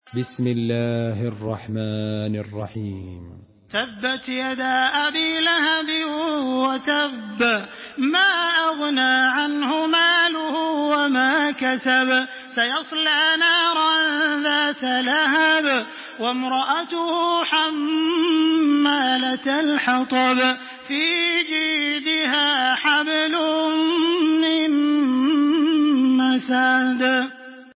تحميل سورة المسد بصوت تراويح الحرم المكي 1426